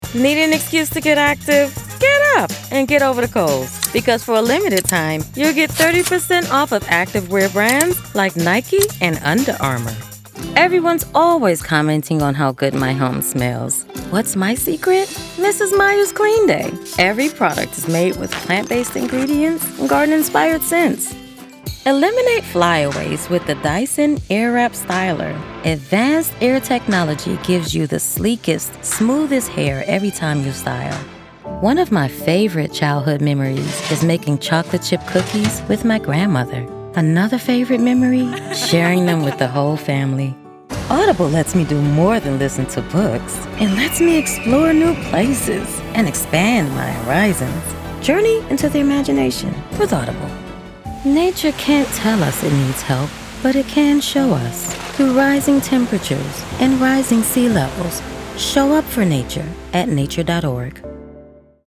Dynamic in personality that shines through regardless of what the topic is.
Commercial demo samples
Middle Aged